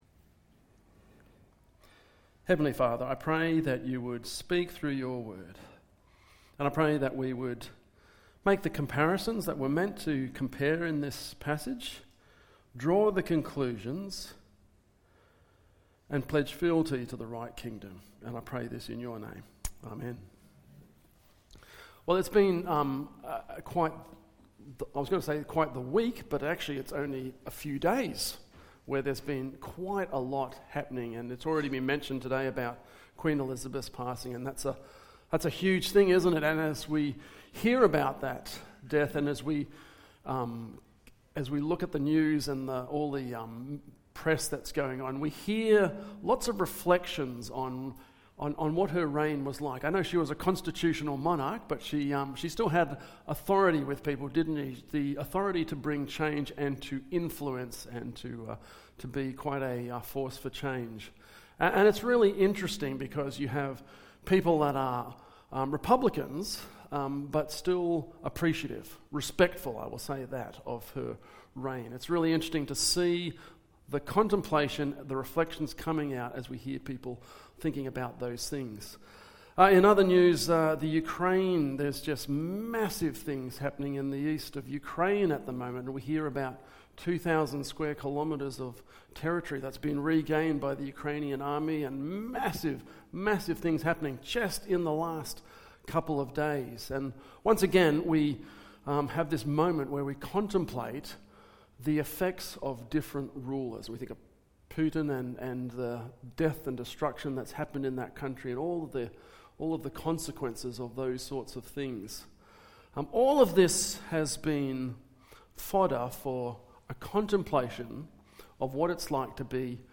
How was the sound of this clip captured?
Western Blacktown Presbyterian Church is now Hope at the Hill meeting at Rooty Hill.